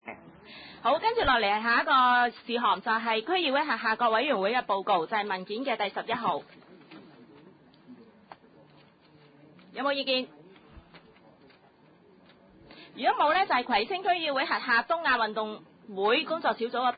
葵青區議會第六十三次會議